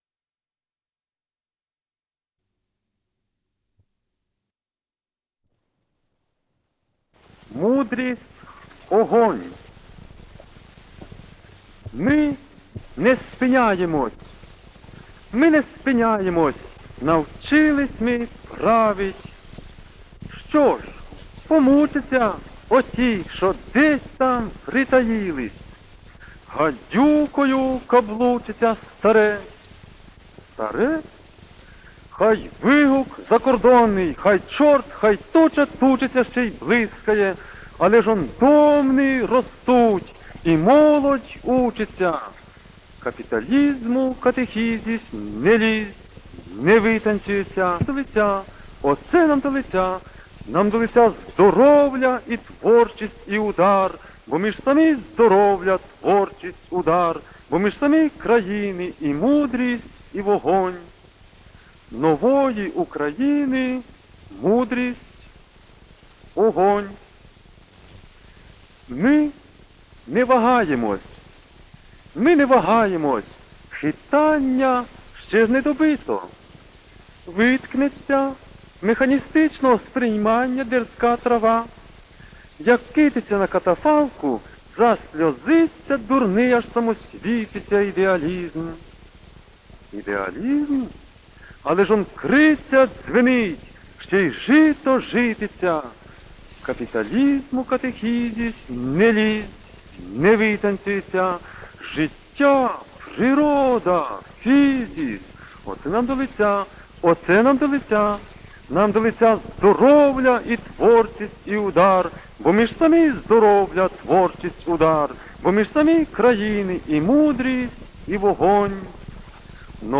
ЛБН-002 — Архивные записи с шоринофона — Ретро-архив Аудио
с профессиональной магнитной ленты
2. Окончание выступления Карла Радека [3:18]
ВариантМоно